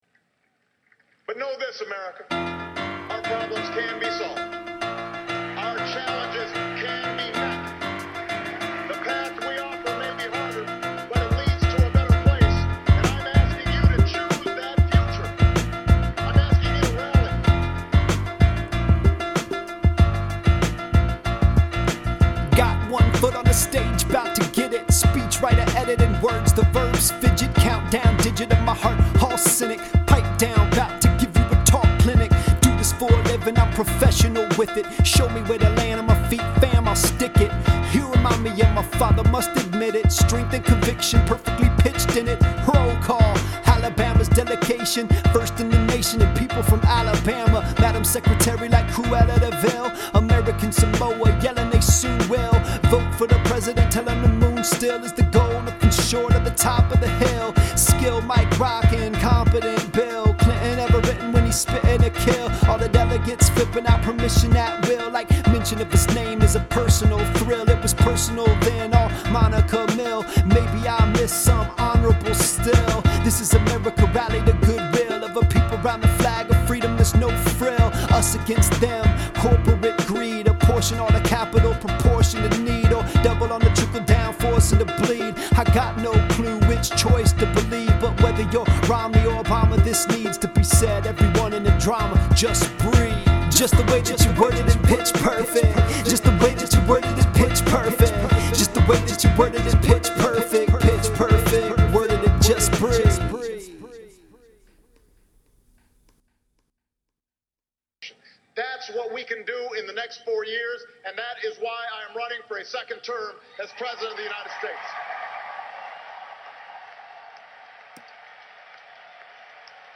Rap news isn’t really his thing.)